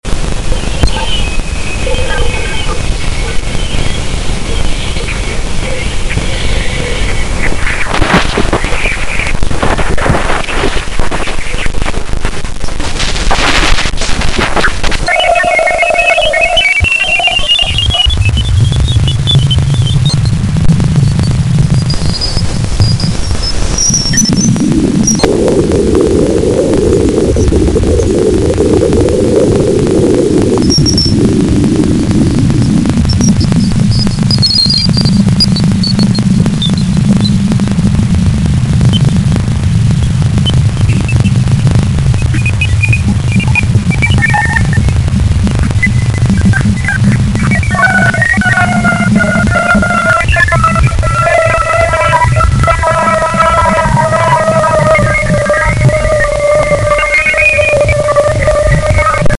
And beware the weird radio emissions Galileo gathered from Jupiter's largest moon, Ganymede.